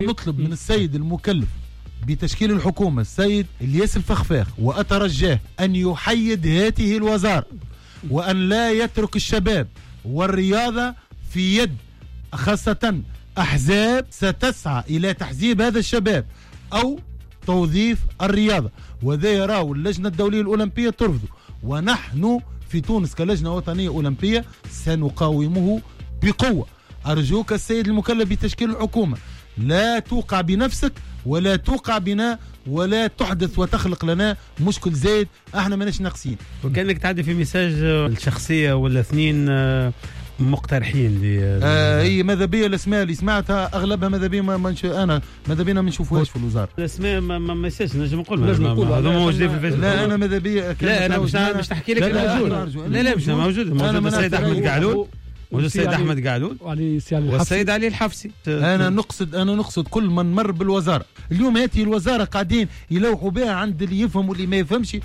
توجه رئيس اللجنة الأولمبية محرز بوصيان لدى حضوره في حصة "قوول" بطلب لرئيس الحكومة المكلف السيد إلياس الفخفاخ بضرورة تحييد وزارة الشباب و الرياضة و إبعادها عن كل الحسابات السياسية.